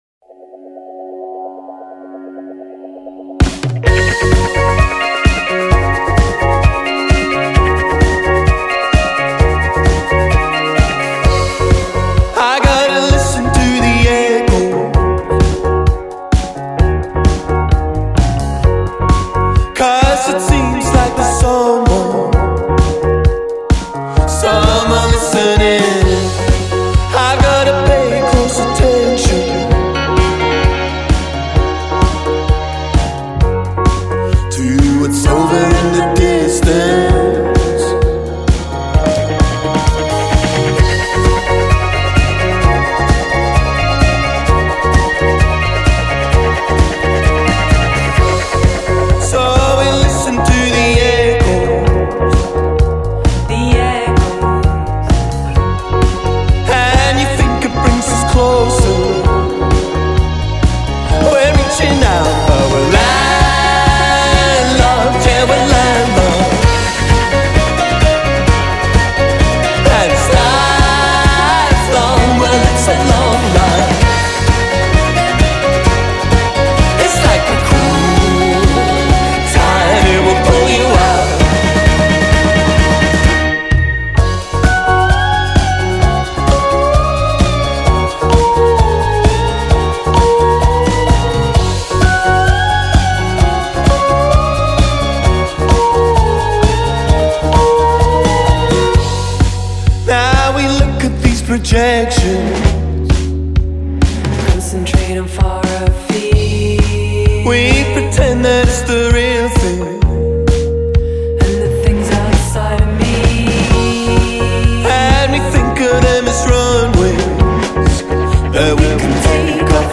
suonano trascinanti e irresistibili come pochi altri.